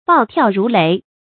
注音：ㄅㄠˋ ㄊㄧㄠˋ ㄖㄨˊ ㄌㄟˊ
暴跳如雷的讀法